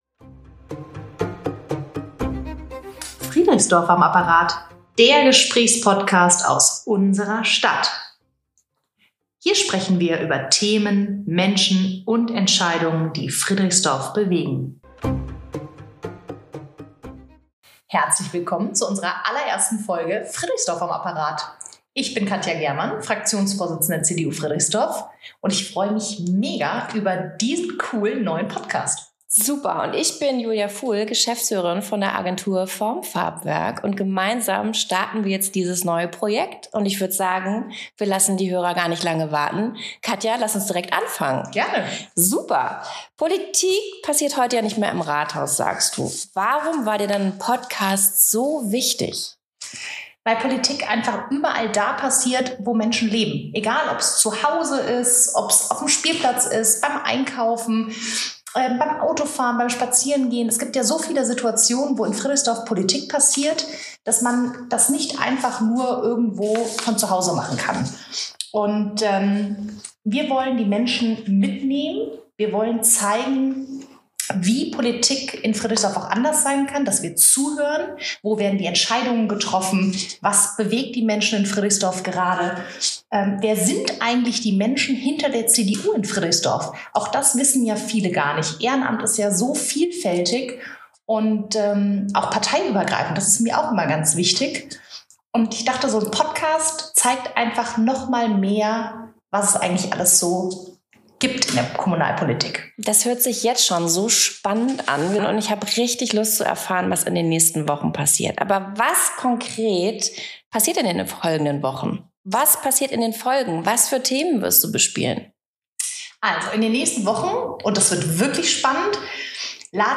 Der Gesprächspodcast aus unserer Stadt